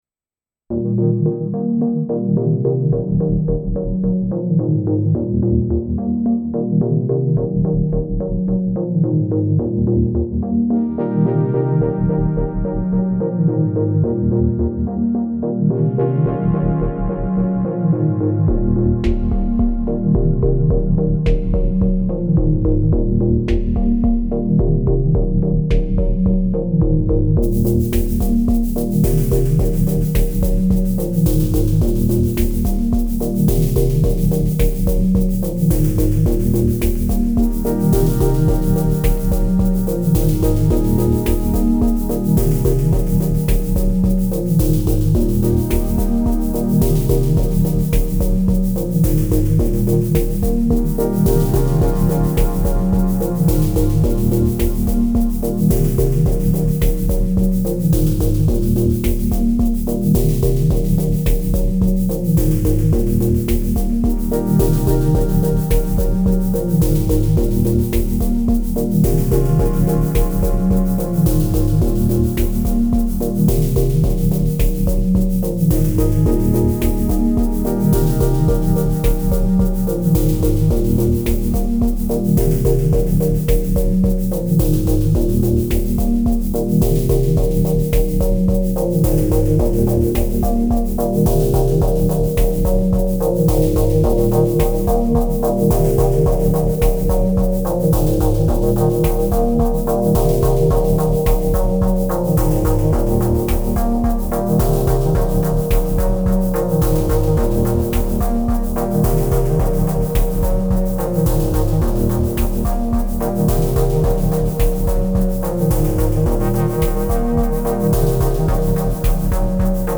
A low-key, soothing tune to bob your head to. Nothing fancy here, just a repeating theme alternating between major and minor. Three tracks of SY raw at the centre.